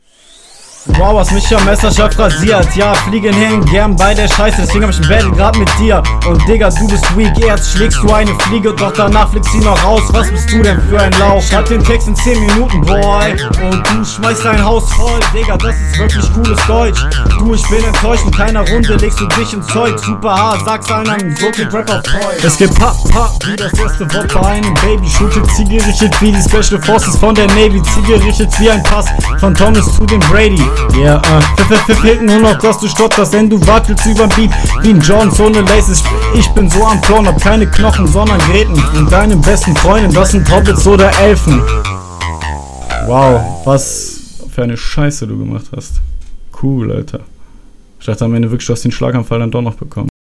Flow: Du flowst wieder routinierter als dein Gegner. Die Betonungen sind teilweise ganz cool.